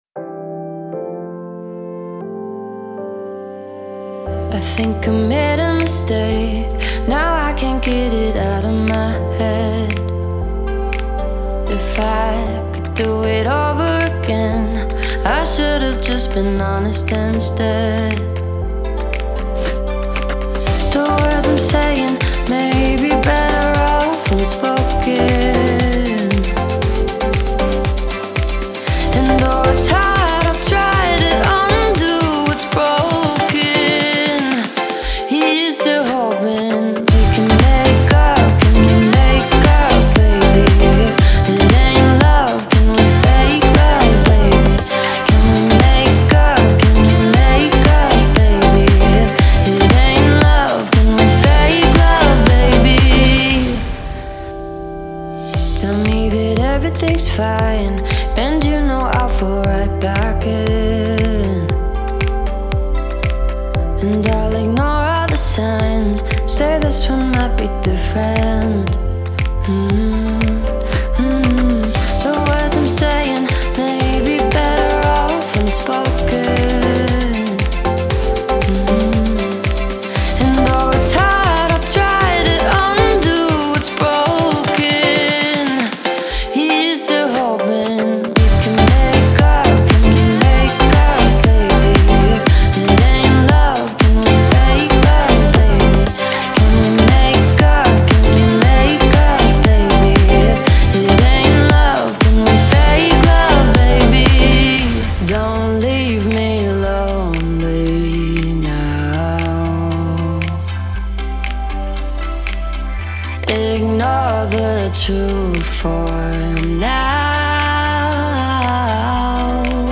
это динамичная трек в жанре EDM